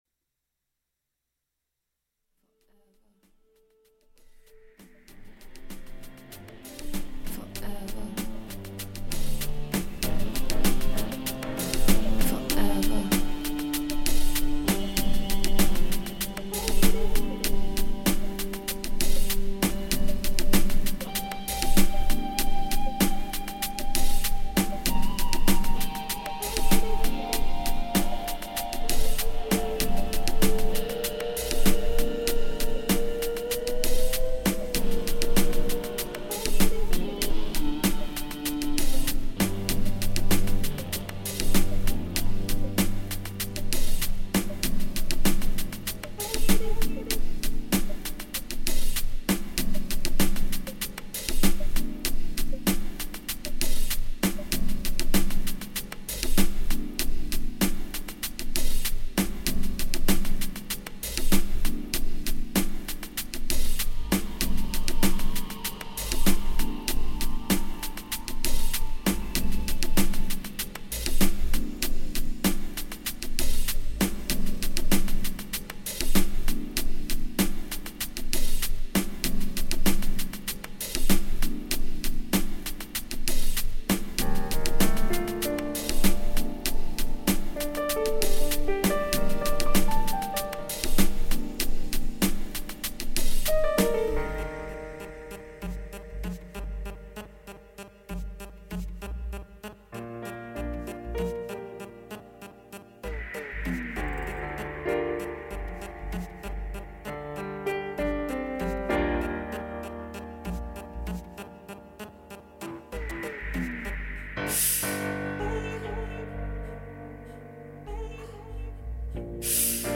Pulsating Groove with Outer Space & Industrial Stabs......